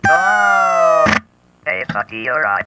Download Free Qbert Sound Effects